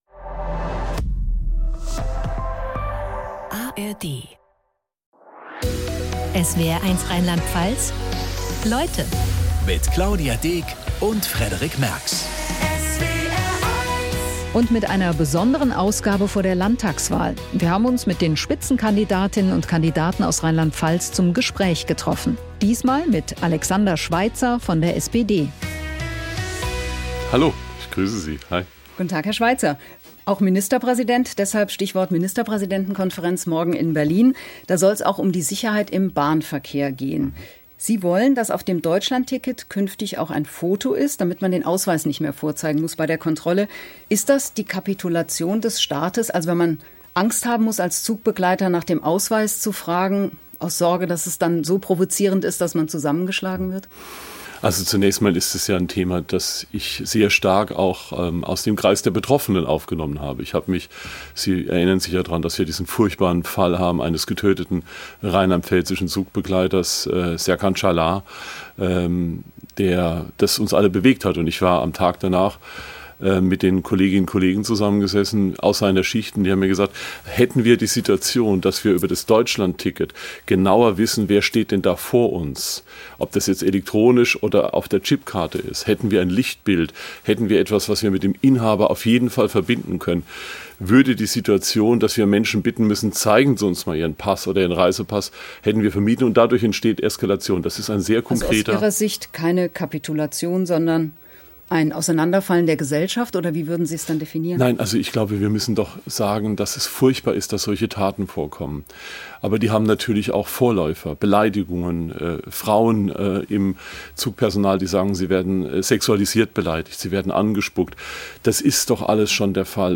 In dieser Folge zu Gast: Alexander Schweitzer von der SPD.